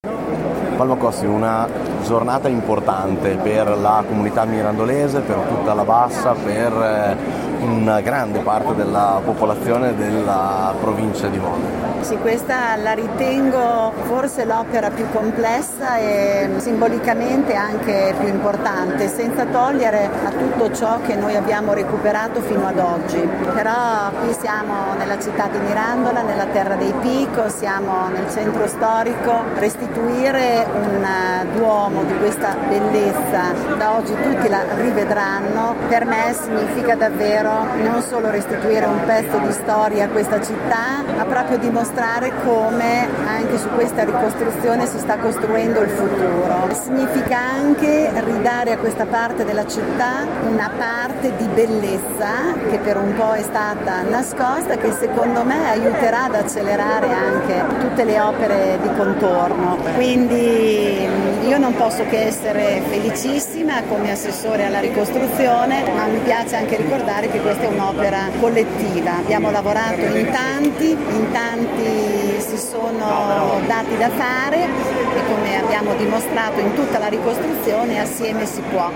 L’Assessore Regionale con delega alla ricostruzione Palma Costi: